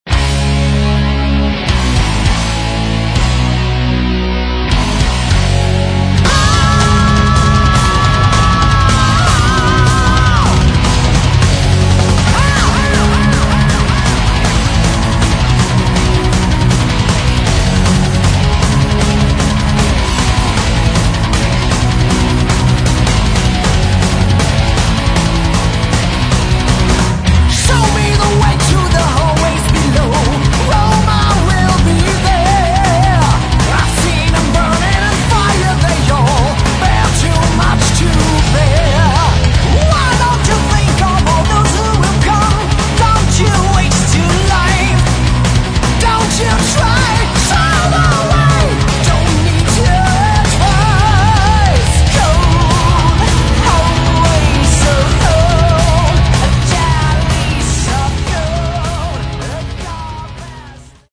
гитары
бас
ударные
клавишные